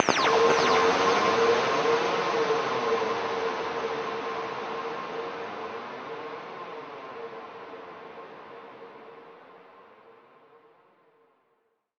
Ambient
1 channel